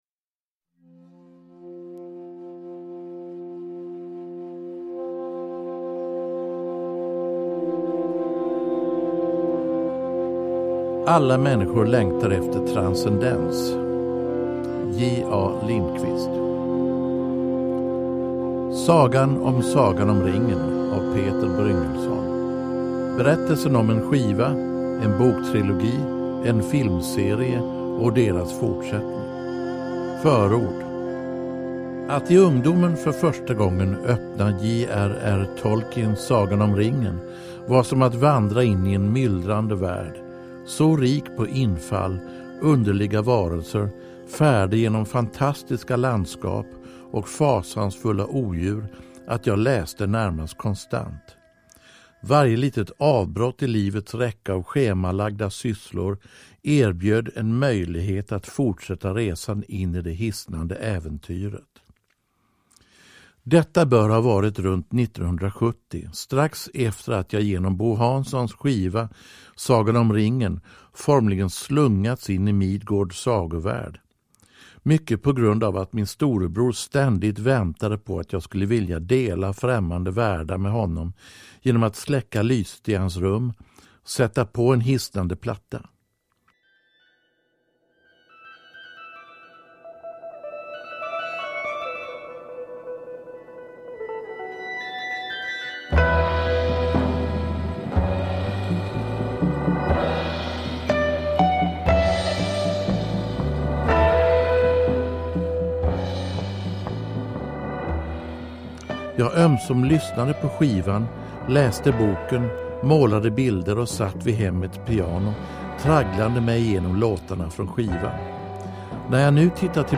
Sagan om Sagan om Ringen – Ljudbok – Laddas ner